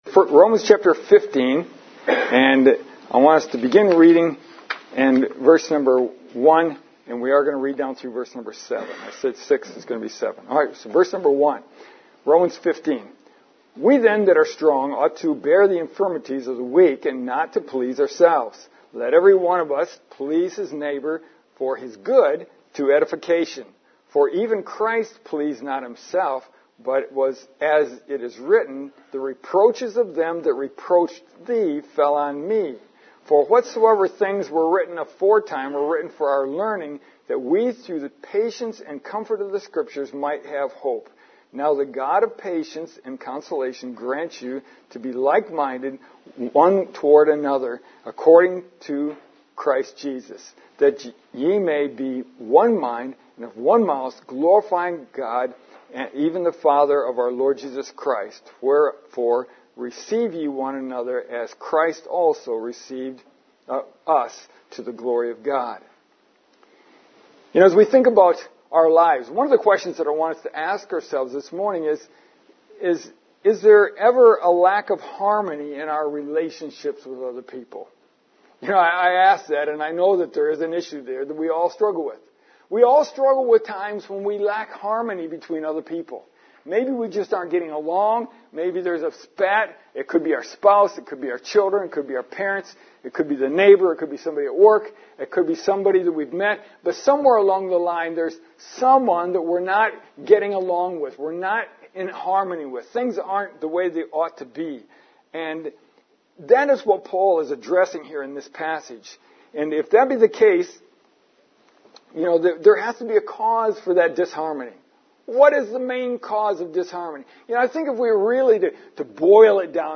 Please note, the sermon was interrupted by an alarm going off 9 minutes into the sermon. We moved to the veranda and finished the service.